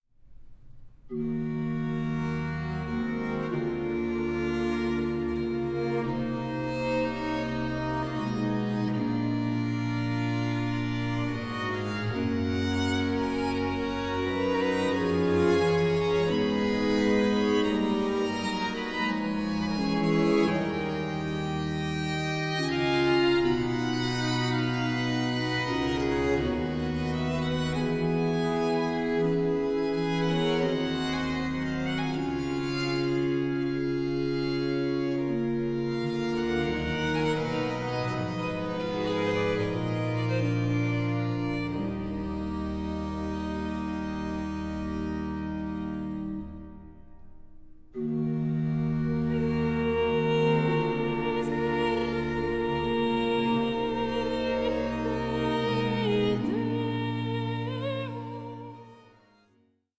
EXUBERANT REQUIEM MASSES FROM THE ANTWERP OF RUBENS
one of today’s most forward-thinking period orchestras